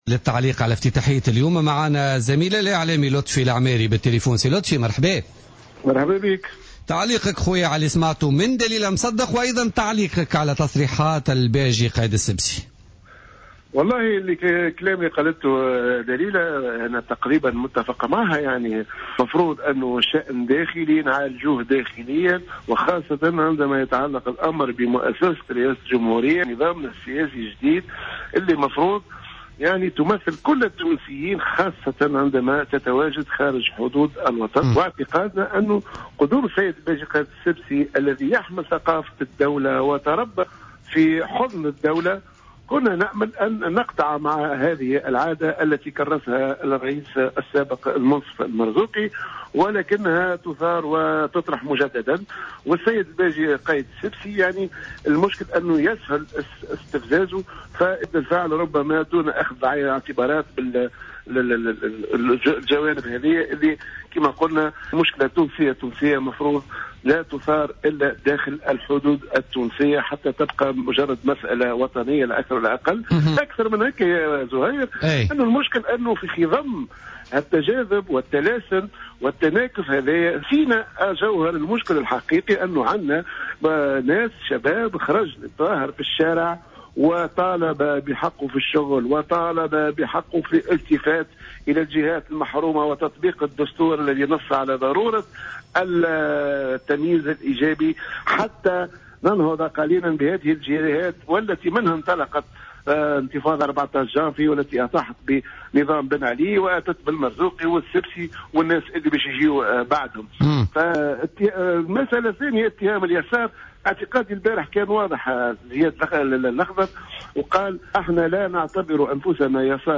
ضيف برنامج بوليتيكا